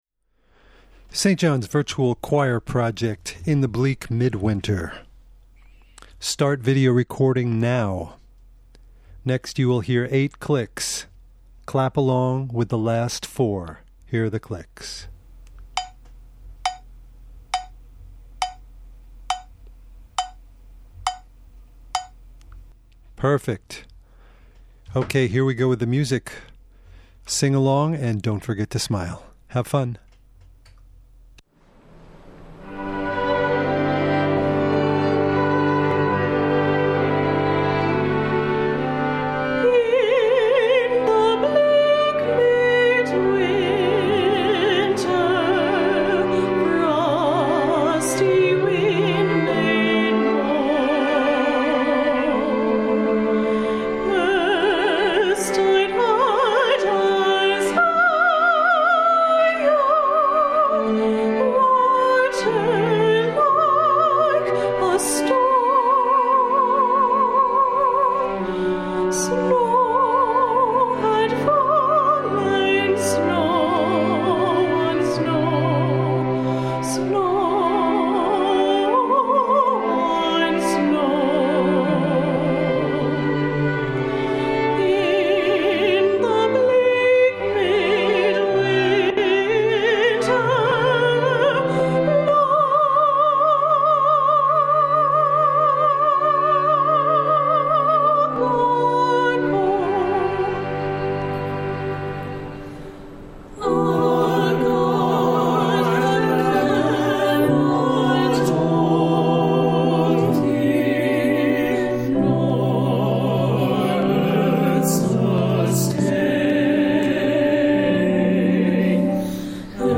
SING-ALONG TRACK
Full-length sing-along - MP3
InTheBleakMidwinter_enhanced_singalong.mp3